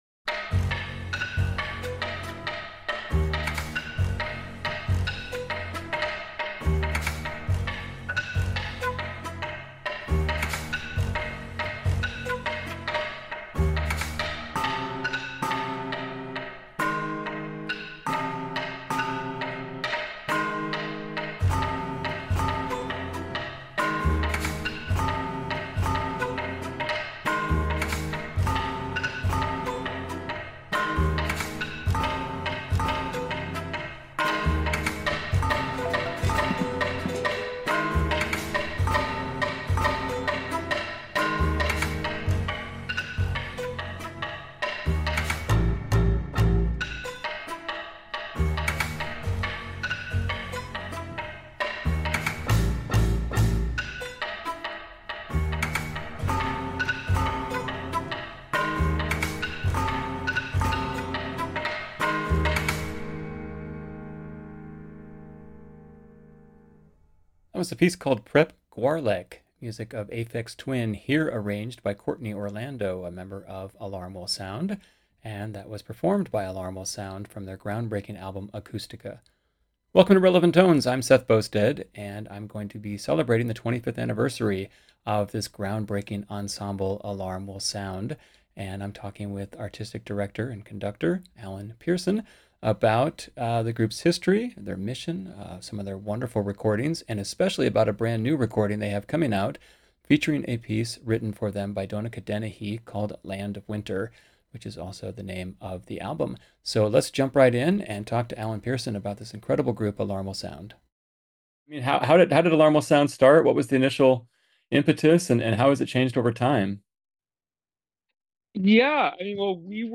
Alarm Will Sound is a ground-breaking 20-member chamber orchestra that challenges and reshapes musical conventions through performances of music by today’s composers.